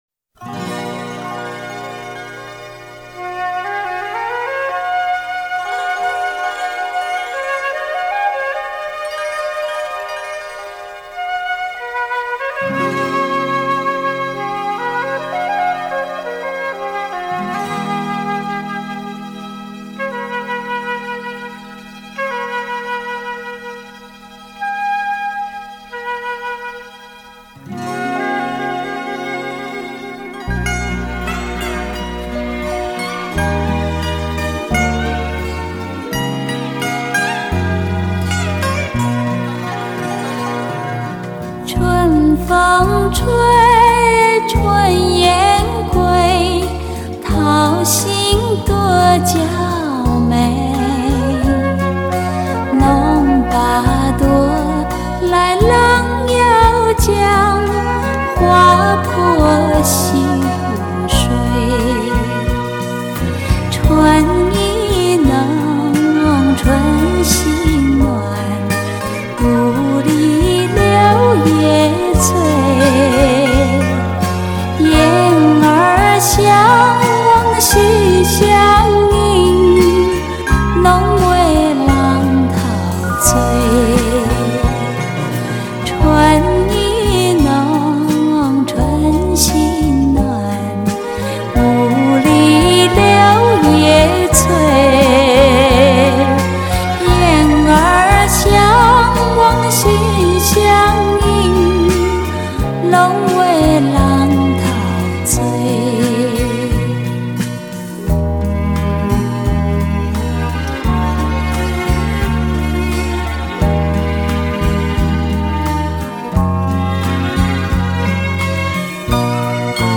采用全属发烧友最期待的原装模拟带来重新炮製的足本，录音是完整原汁原味的高烧级製品，更不作任何数码修饰处理。
2.现场模拟录音，1：1的直刻母带技术。